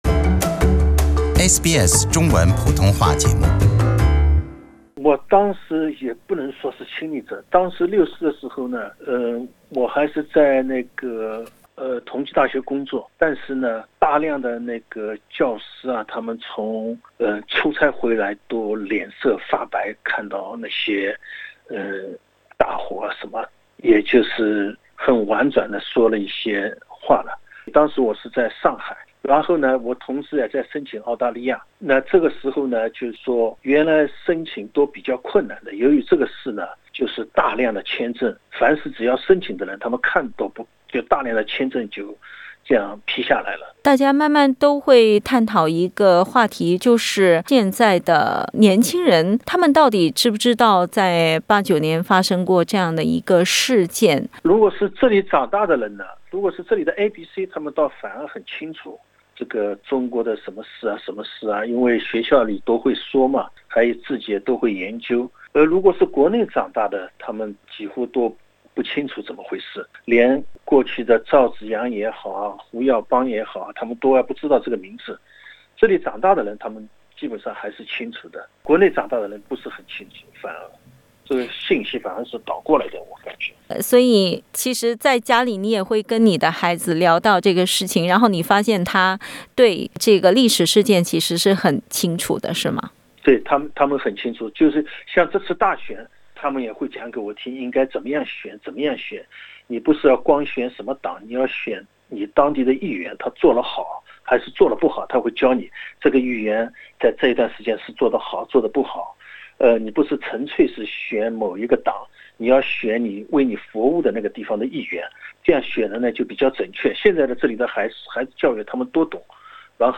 以上采访仅为嘉宾观点，不代表本台立场。